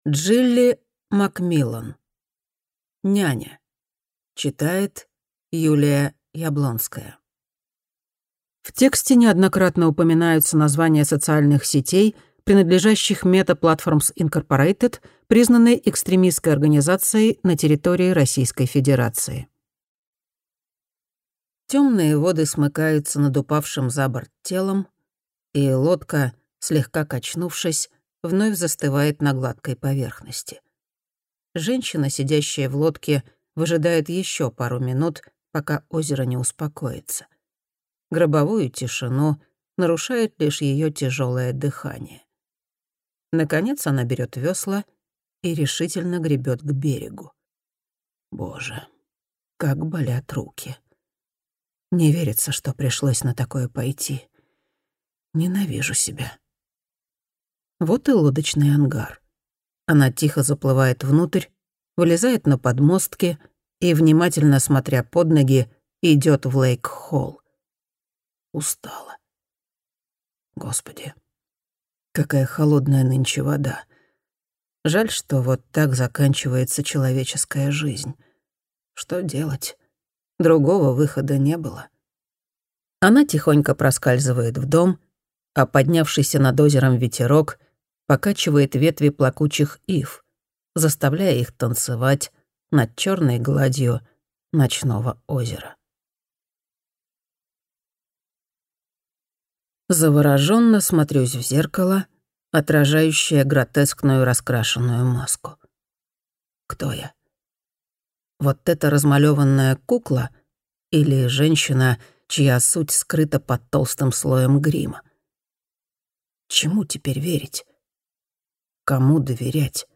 Аудиокнига Няня | Библиотека аудиокниг